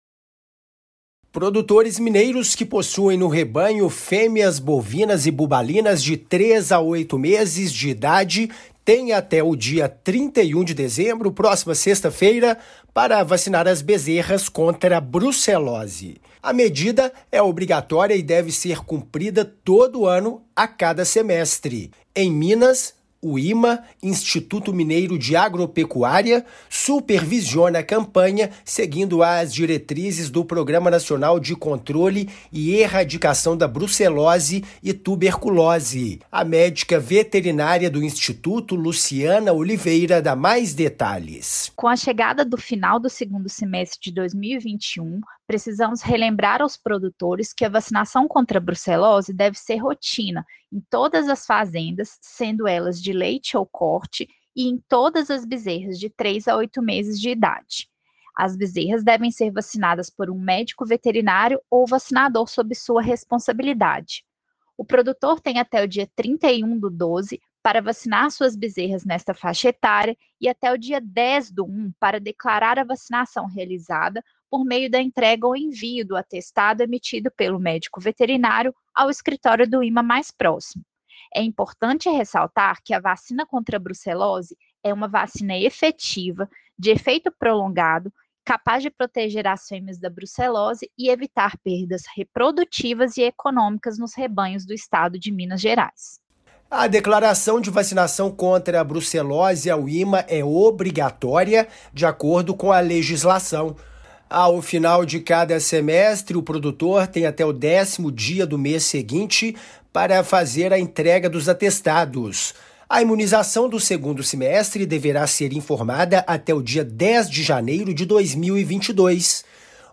Produtores devem imunizar fêmeas bovinas e bubalinas. Ouça a matéria de rádio.